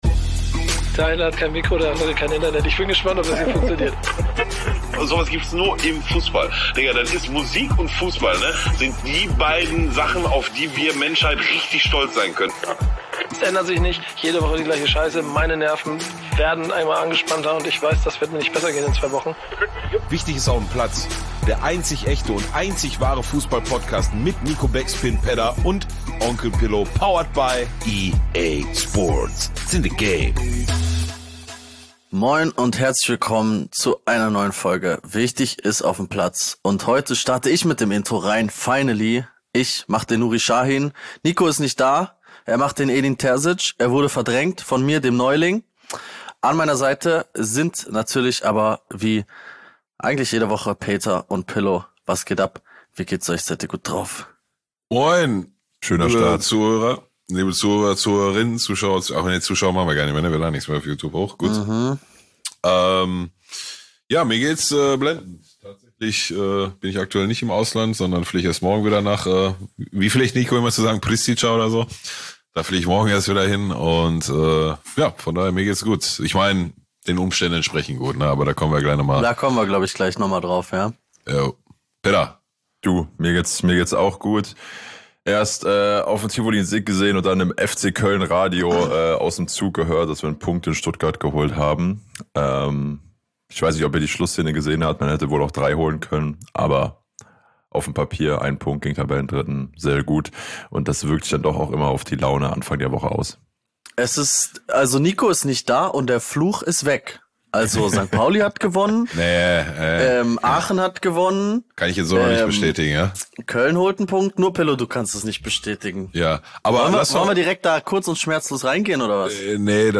wir sprechen zu dritt über die Geschehnisse der vergangenen Tage.